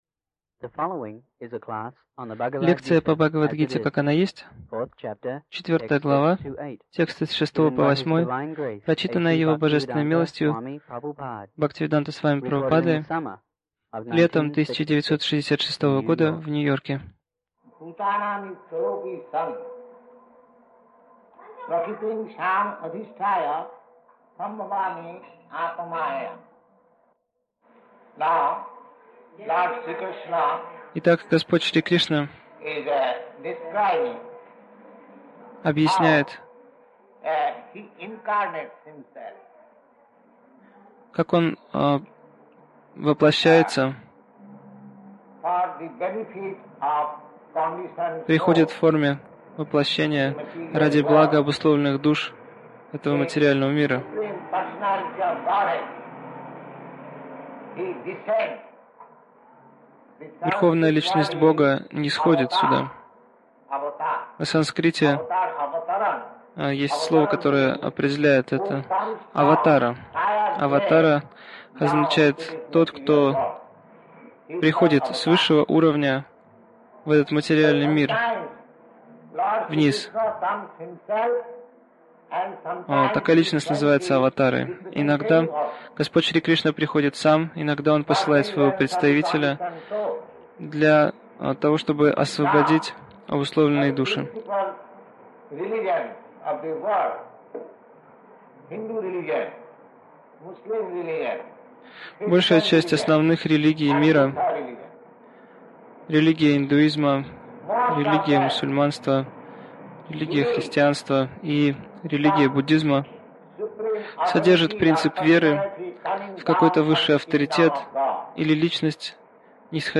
Милость Прабхупады Аудиолекции и книги 20.07.1966 Бхагавад Гита | Нью-Йорк БГ 04.06-08 Загрузка...